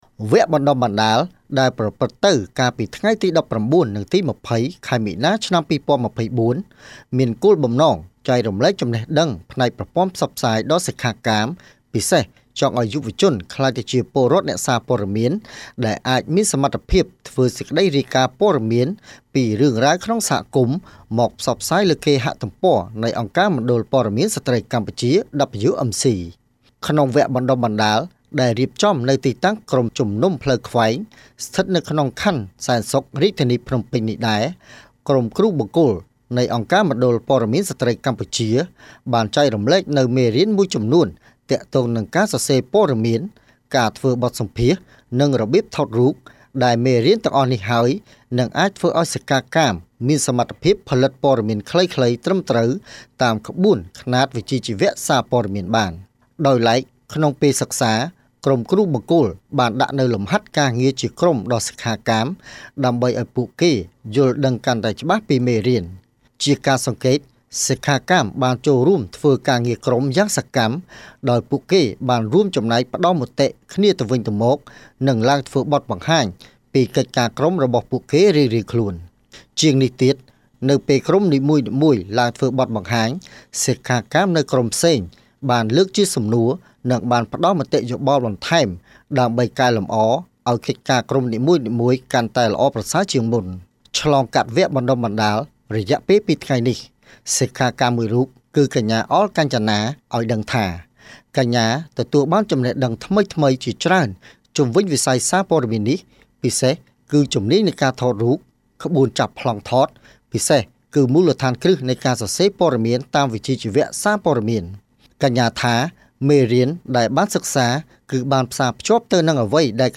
ជូនសេចក្តីរាយការណ៍